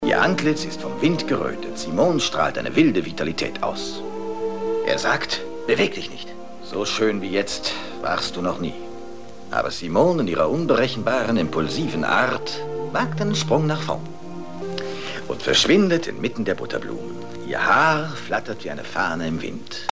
Deutscher Sprecher der Originalsynchro: Gert Günther Hoffmann
Hörprobe des deutschen Synchronschauspielers (311 Kb)